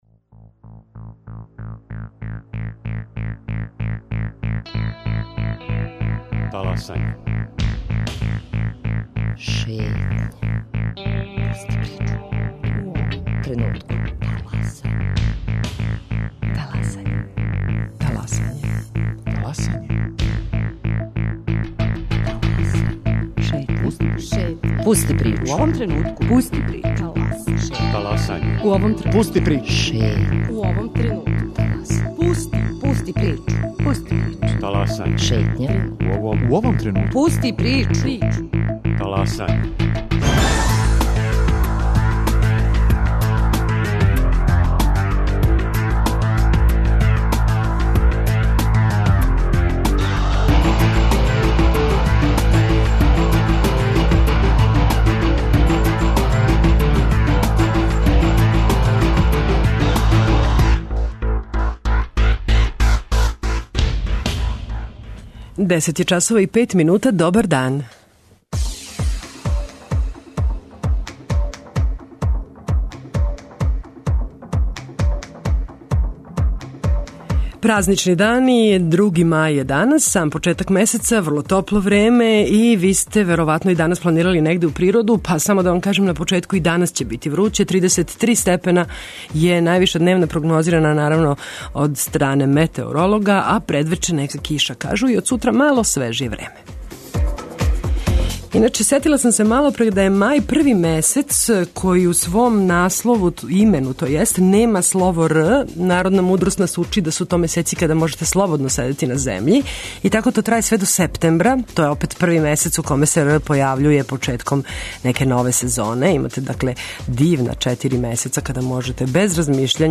Добра музика, јављање репортера из градова широм Србије са најпознатијих и оних мање познатих излетишта! Причаћемо како је протекао први празнични дан, као и о припремама за најрадоснији хришћански празник - Ускрс.